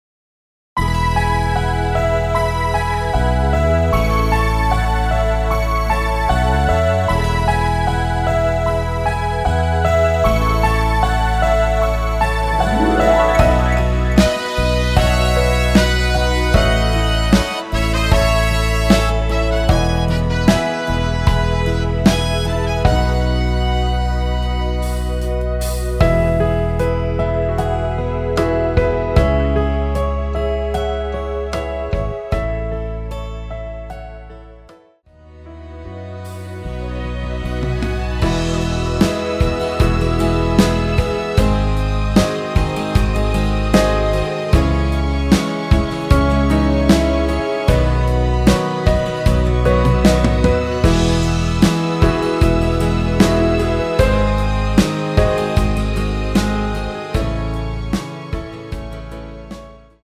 여성분이 부르실수 있는 키로 제작 하였습니다.(미리듣기 참조)
멜로디 MR이란
앞부분30초, 뒷부분30초씩 편집해서 올려 드리고 있습니다.
중간에 음이 끈어지고 다시 나오는 이유는